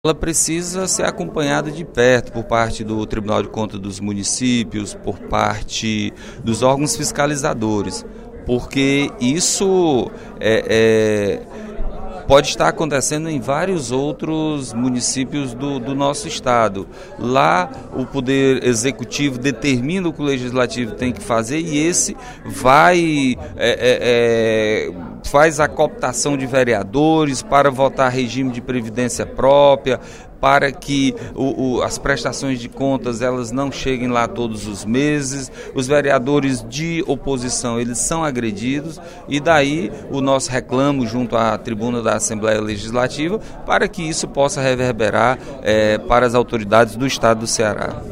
O deputado Nenen Coelho (PSD) pediu, no primeiro expediente da sessão plenária desta quinta-feira, (27/02), ao Tribunal de Contas dos Municípios uma especial atenção nas investigações que estariam em curso no município de Santana do Cariri. Segundo ele, há fortes indícios de superfaturamento. O parlamentar também pediu que fosse averiguado o processo de municipalização do sistema previdenciário dos servidores públicos locais.